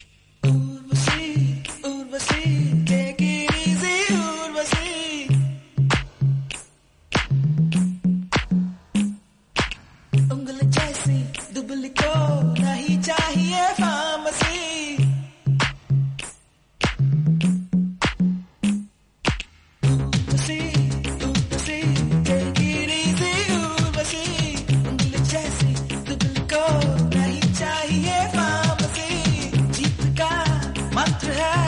tamil ringtonebest ringtonedance ringtonesouth ringtone
best flute ringtone download